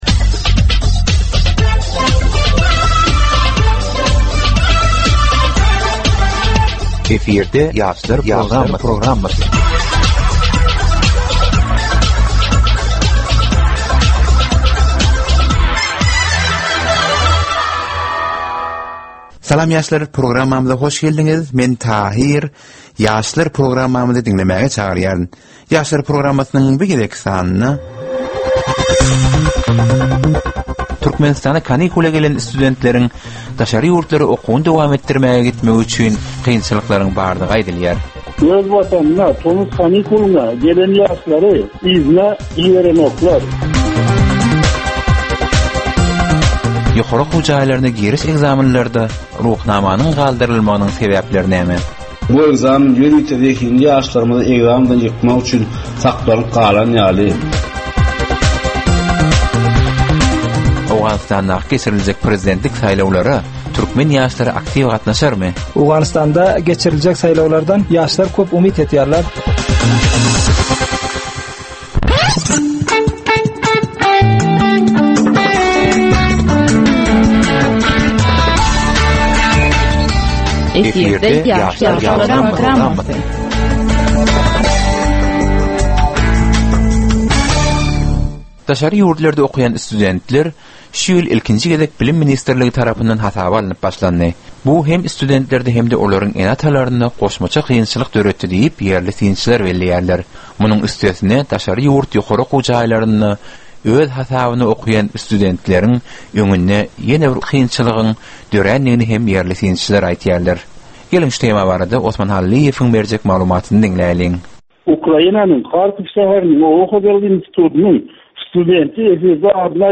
Geplesigin dowmynda aýdym-sazlar hem esitdirilýär.